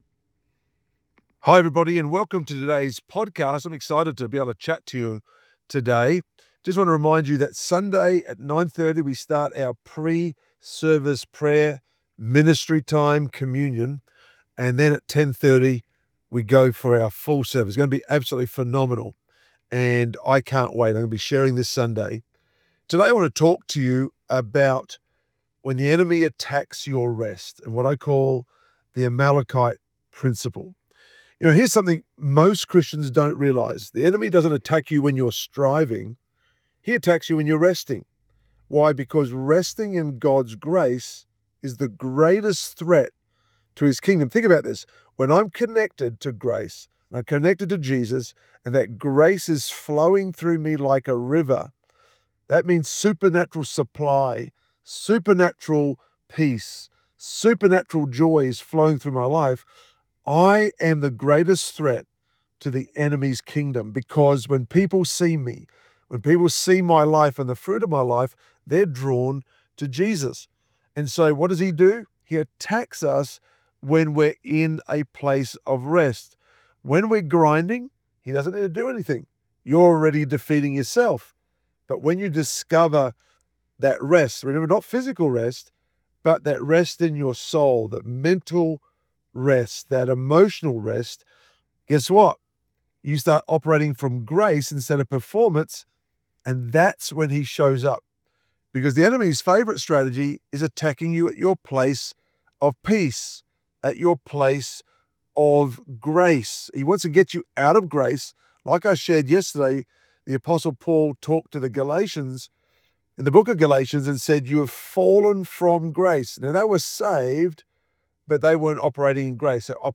daily devotionals on grace